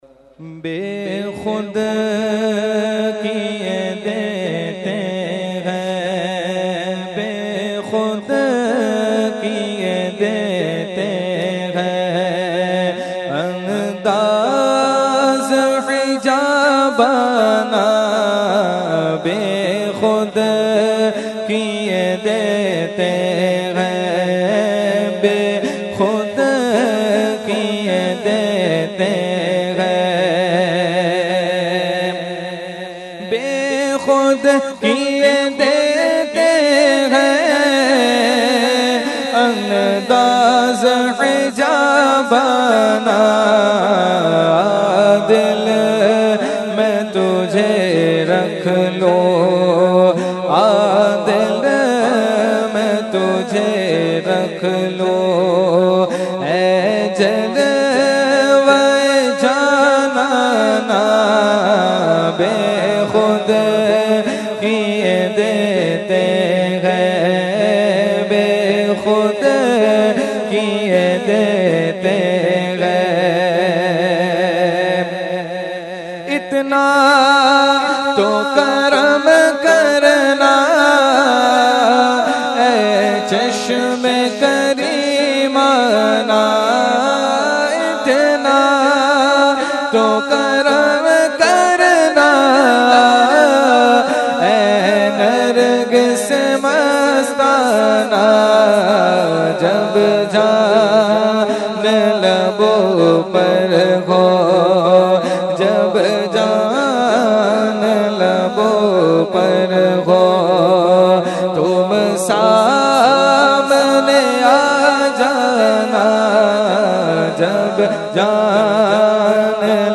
Category : Naat | Language : UrduEvent : Urs Makhdoome Samnani 2020